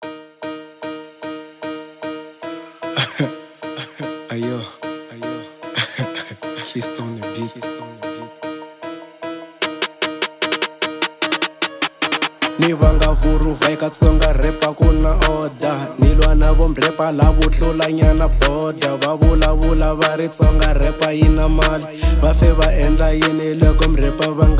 00:25 Genre : Hip Hop Size